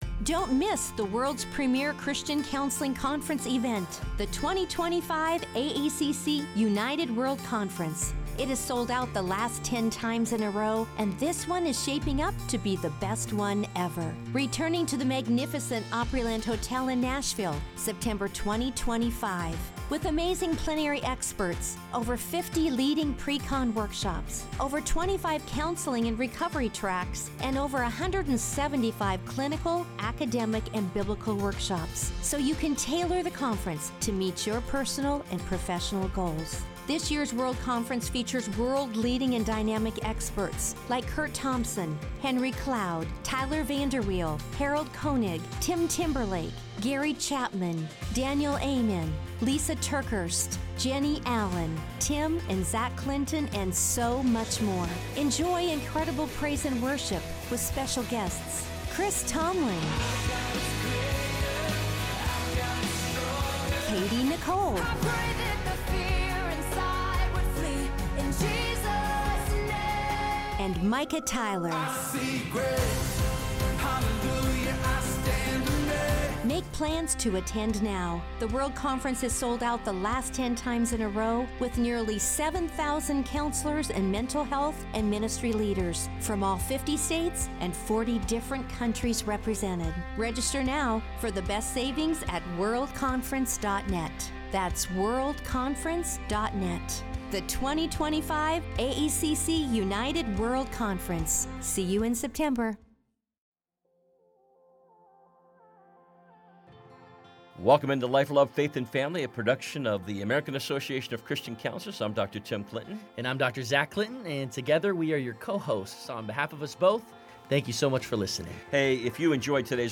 for a conversation about living boldly for Christ.